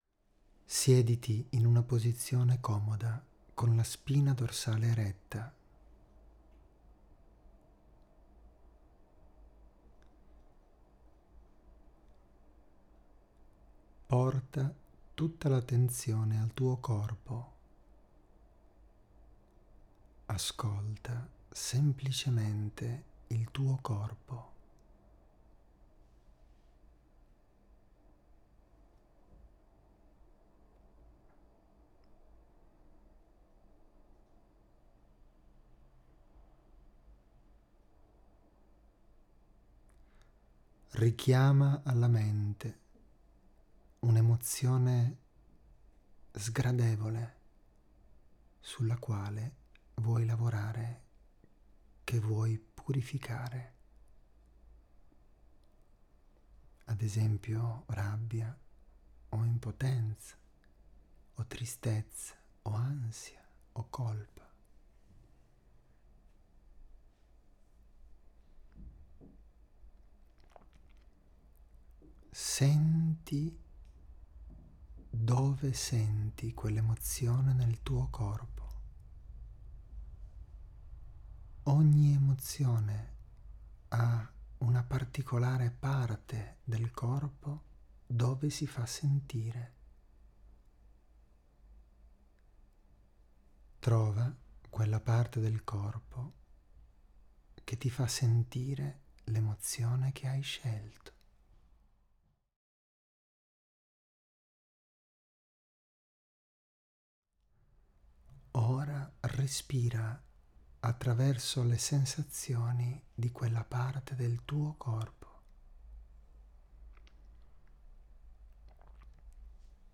Traccia Guidata RESPIRO CHE LIBERA